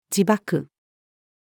自爆-suicide-bombing-(eg-crashing-one's-plane-into-a-target)-female.mp3